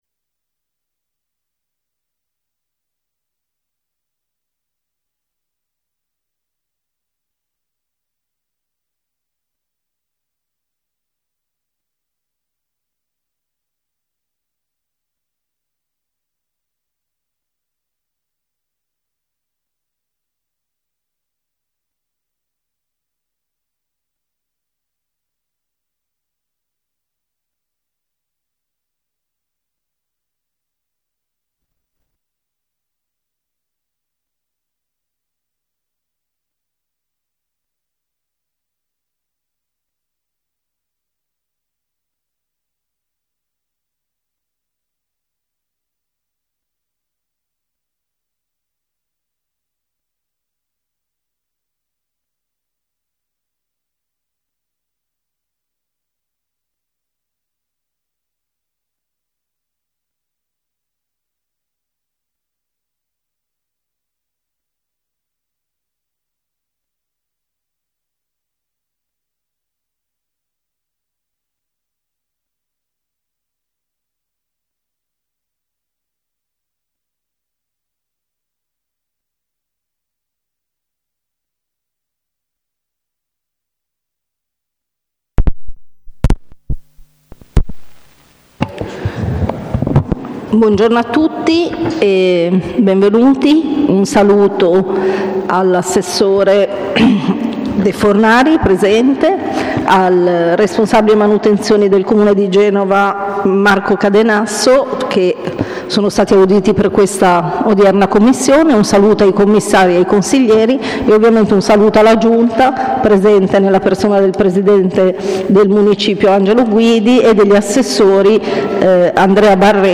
Luogo: Piazza Manzoni, 1 - Sala del Consiglio
Convocazioni: convocazione_commissione_ii_del_20.01.25.pdf Audio seduta: dr0000_0132.mp3